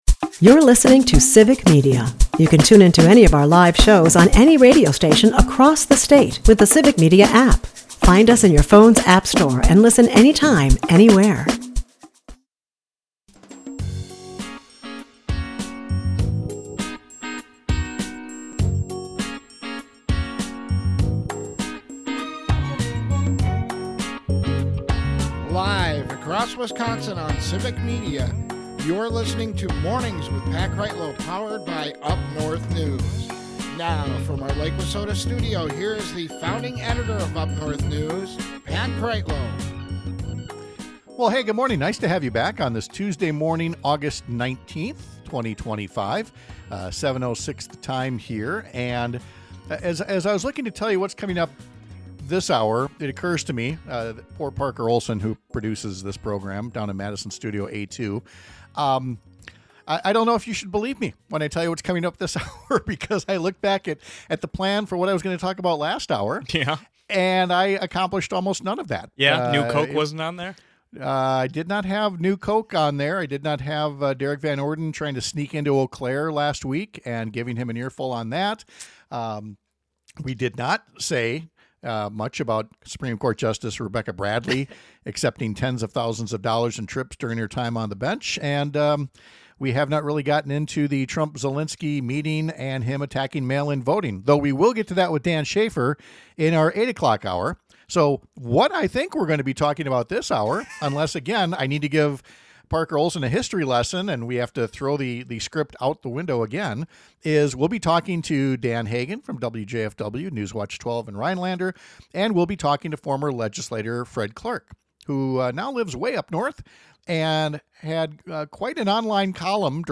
We’ll talk to former state legislator Fred Clark about how ridiculous certain Wisconsin politicians looked in sending a letter to Canada to complain about, you know, their country burning down. We’ll fact-check the people who want to blame everything other than a changing climate for everything that’s changing in our climate.